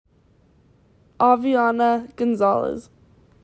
⇓ Name Pronunciation ⇓